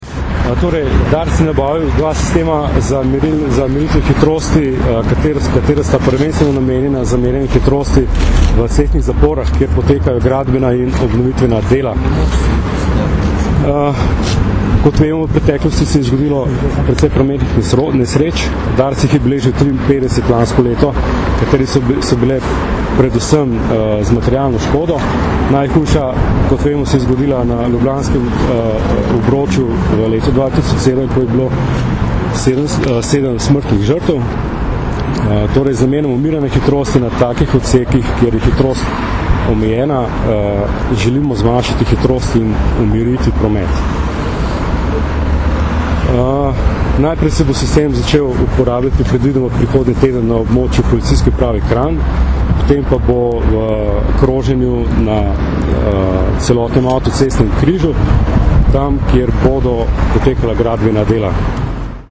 Zvočna izjava